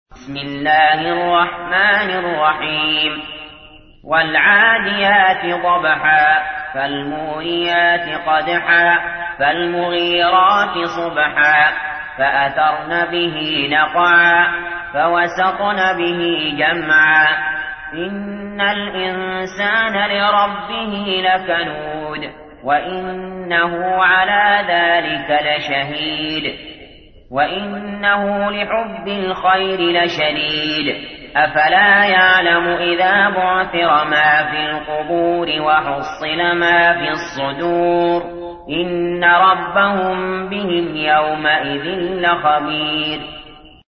تحميل : 100. سورة العاديات / القارئ علي جابر / القرآن الكريم / موقع يا حسين